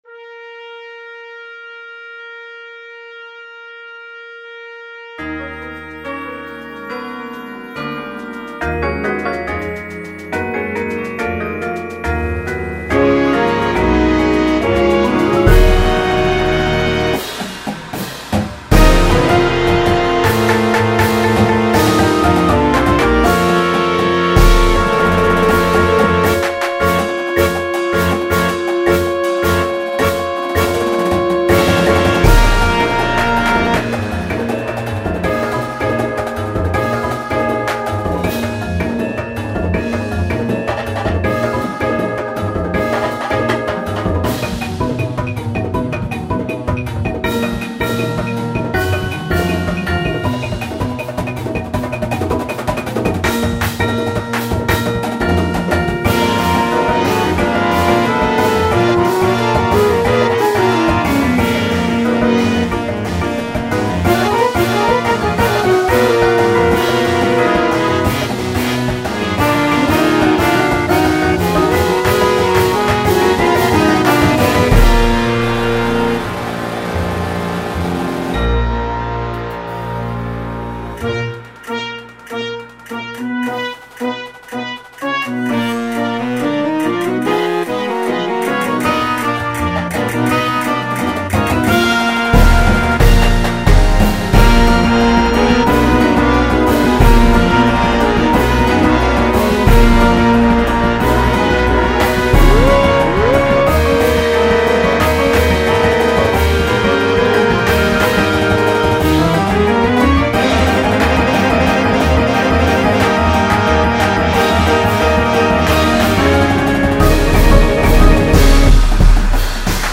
Spanish flare and flavor abound in this sizzling show
Flute
Alto Saxophone
Trumpet 1, 2
Trombone 1, 2
Tuba
Snare Line
Marimba
Synth 1, 2
Drum Set